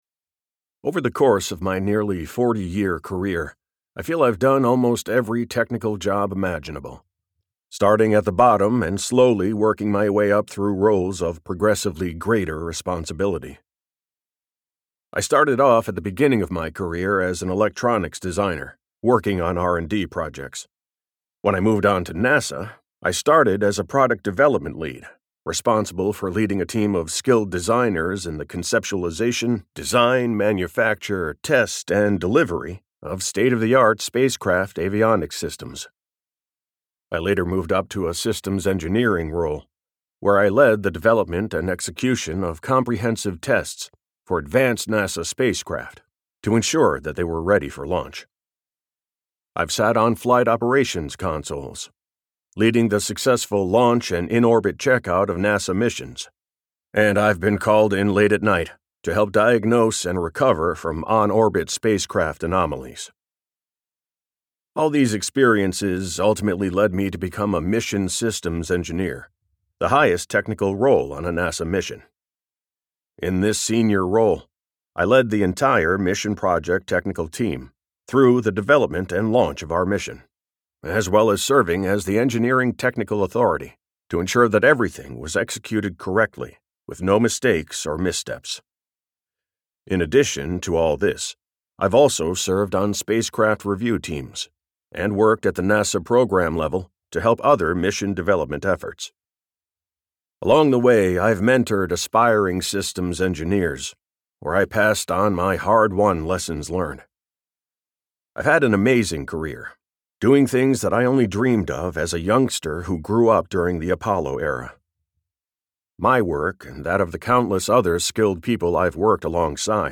Nice Guys Finish Last and Other Workplace Lies - Vibrance Press Audiobooks - Vibrance Press Audiobooks